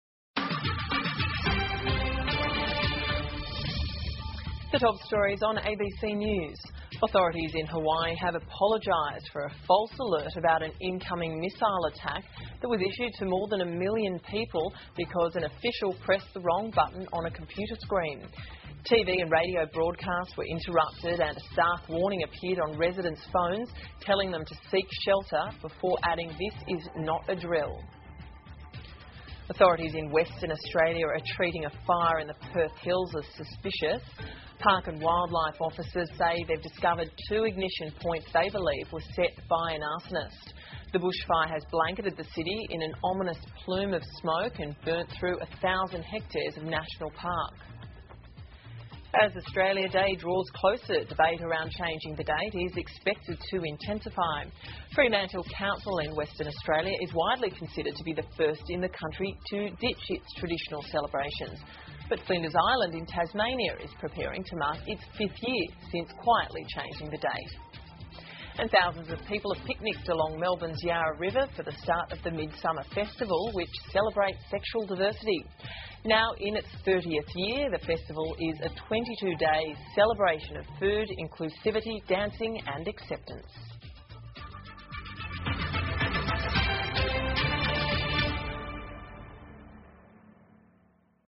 澳洲新闻 (ABC新闻快递) 工作人员按错钮 夏威夷误发导弹警报引恐慌 听力文件下载—在线英语听力室